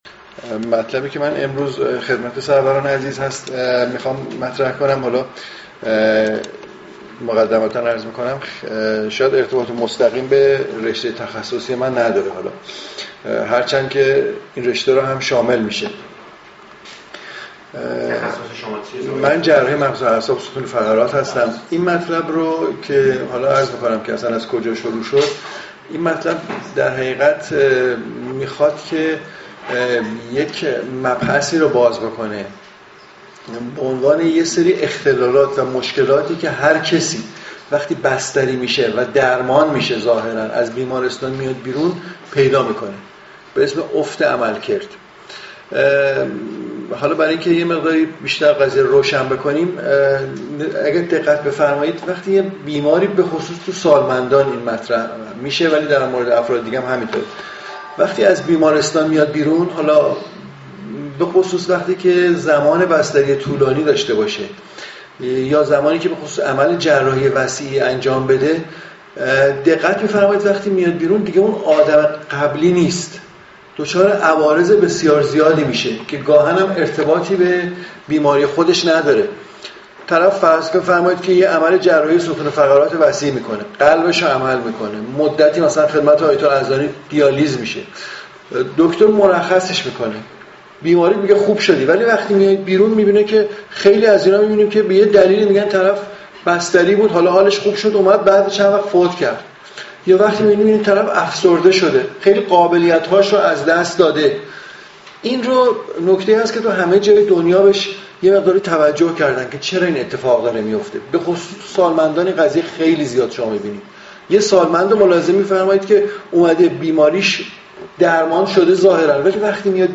سخنرانی جلسه عمومی مجمع خیرین بهداشت و سلامت استان اصفهان